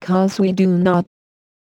Techno / Voice / VOICEFX179_TEKNO_140_X_SC2.wav